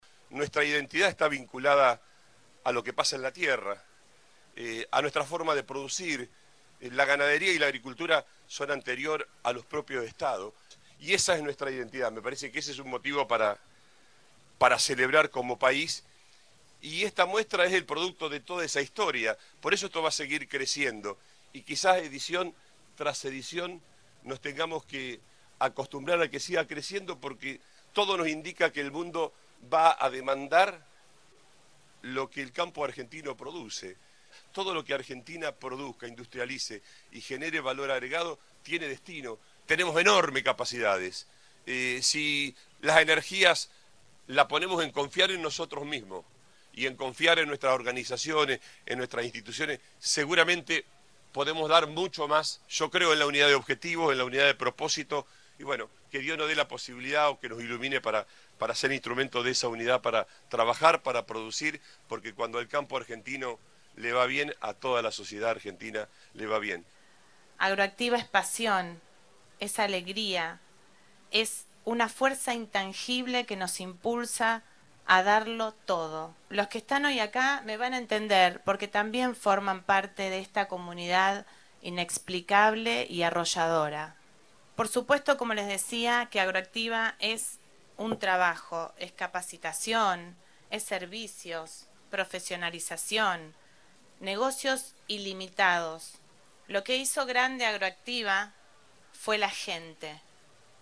Perotti inauguró AgroActiva